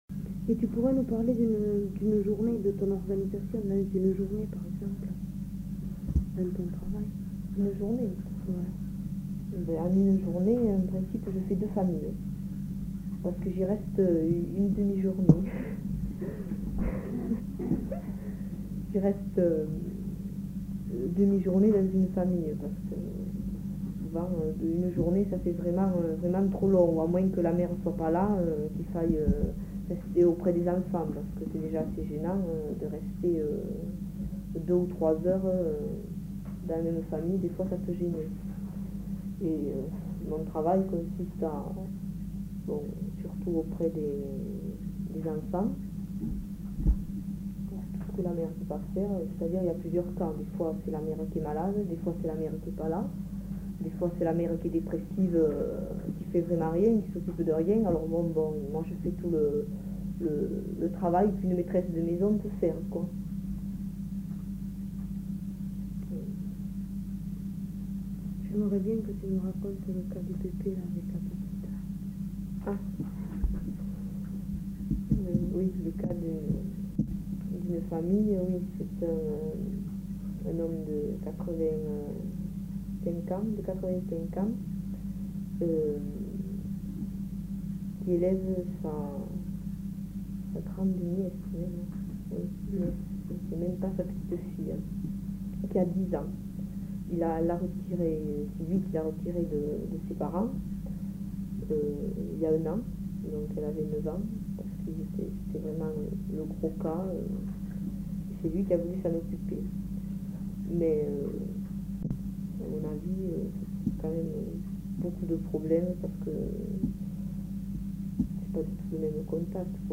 Aire culturelle : Savès
Lieu : Garravet
Genre : récit de vie